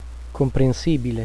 L'accento cade sulla terzultima sillaba con i seguenti suffissi:
comprensìbile, cedévole
comprensibile.wav